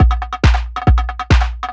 Index of /90_sSampleCDs/Best Service ProSamples vol.45 - Techno ID [AIFF, EXS24, HALion, WAV] 1CD/PS-45 AIFF Techno ID/PS-45 AIF loops/AIF drum-loops/AIF main-version